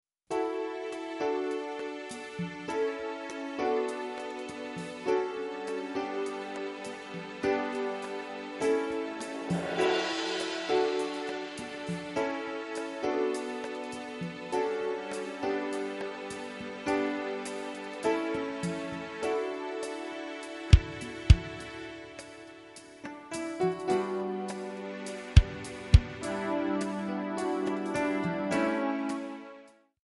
D
Backing track Karaoke
Pop, 1990s